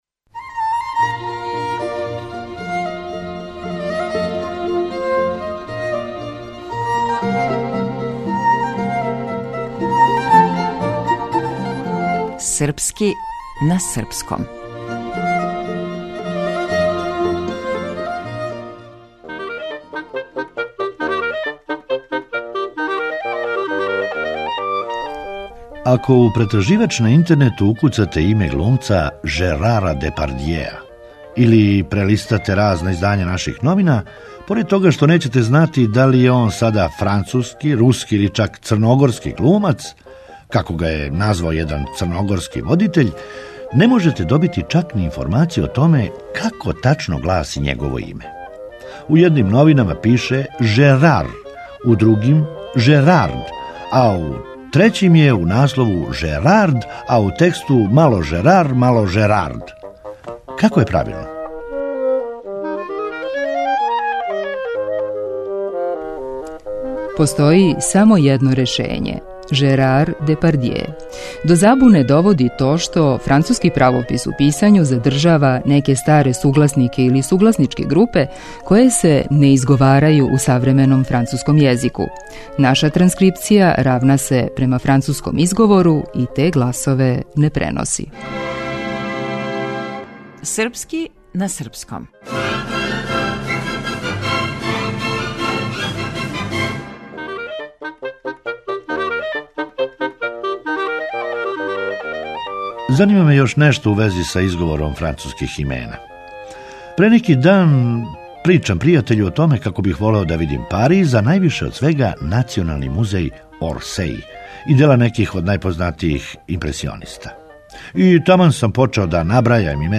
Драмски уметник: